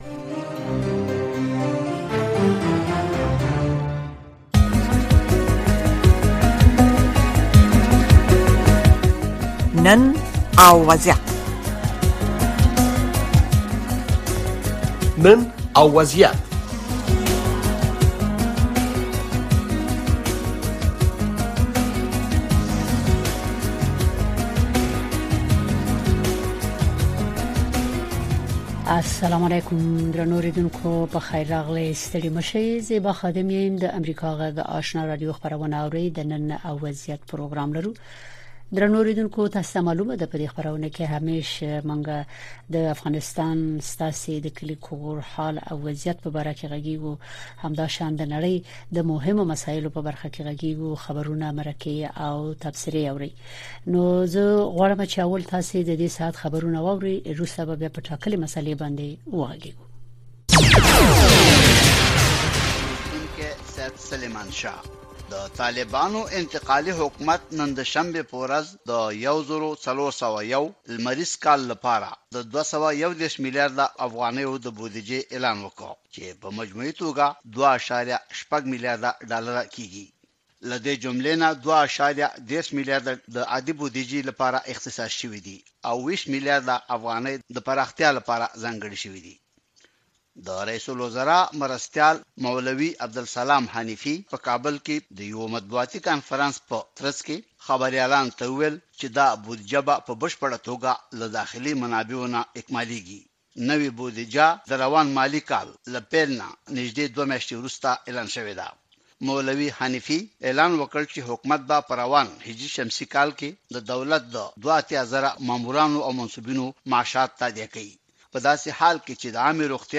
د نړۍ سیمې او افغانستان په روانو چارو او د نن په وضعیت خبرونه، راپورونه، مرکې او تحلیلونه